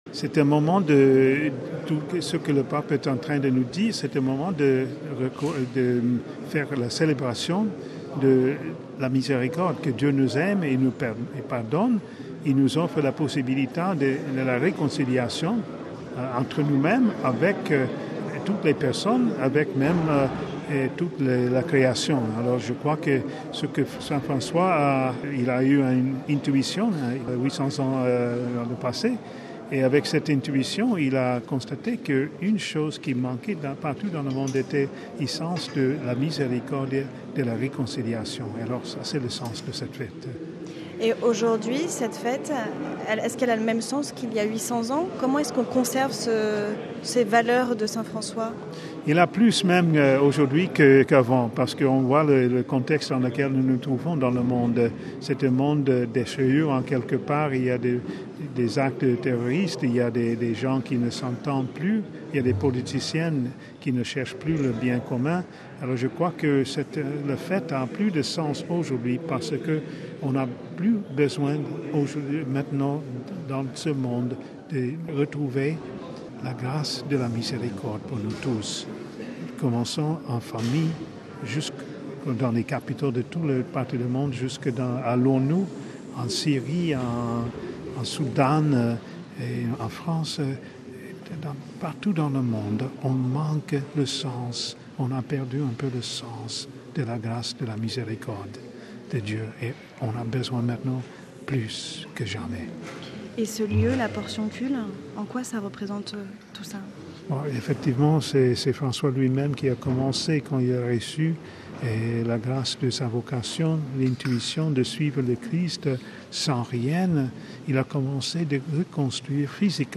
(RV) Entretien - Le Pape se rend à Assise, en Ombrie, ce jeudi 4 août, pour la deuxième fois de son pontificat.